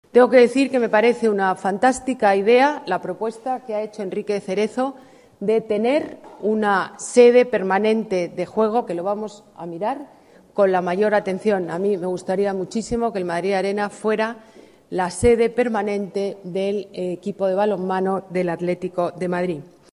Nueva ventana:Declaraciones de Ana Botella, alcaldesa de Madrid